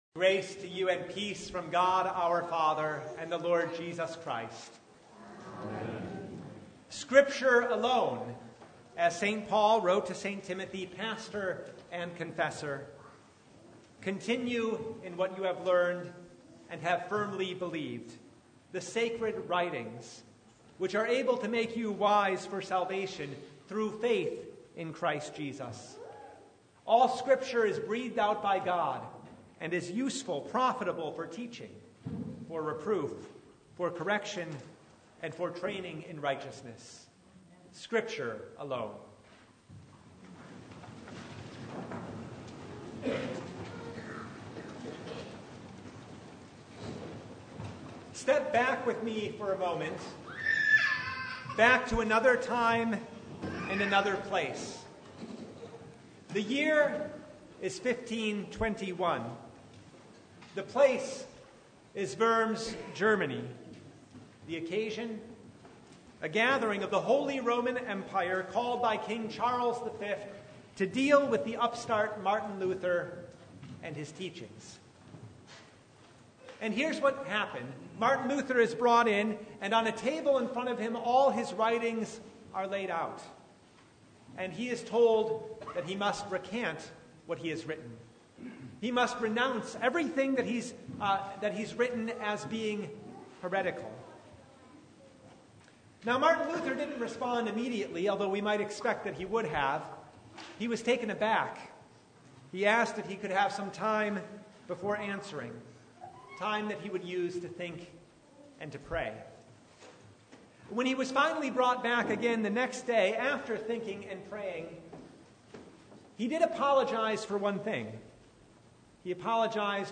Service Type: Reformation
Sermon Only